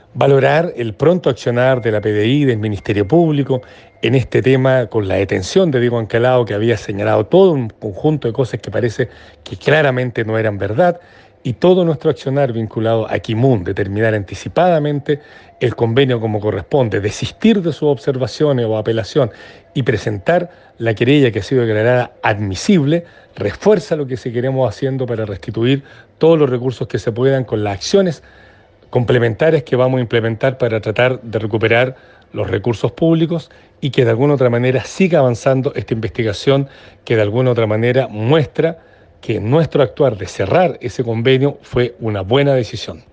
Frente a esta situación durante esta mañana, el Gobernador Patricio Vallespin indicó se valora el pronto accionar de la Policía de Investigaciones y del Ministerio Público en este tema, agregando que las explicaciones que había dado Diego Ancalao eran falsas, lo que se demuestra con su detención.